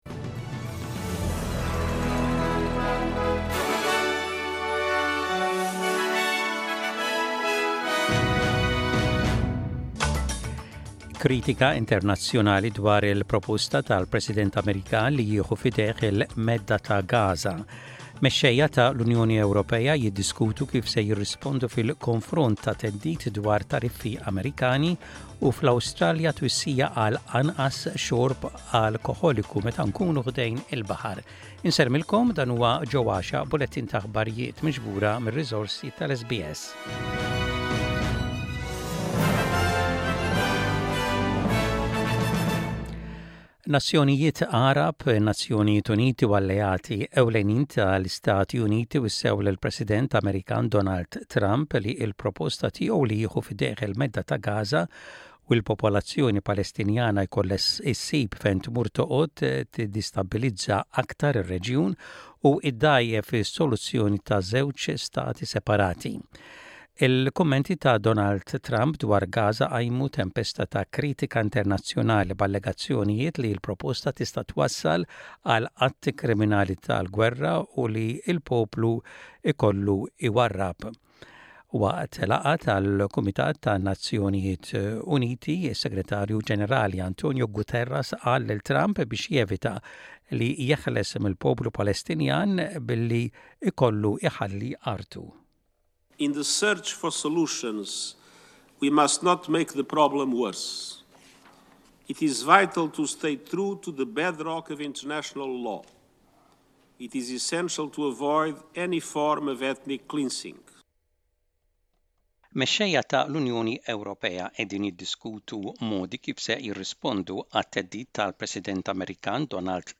SBS Radio | Aħbarijiet bil-Malti: 07.02.25